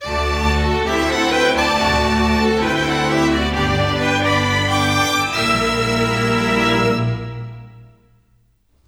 Rock-Pop 01 Strings 04.wav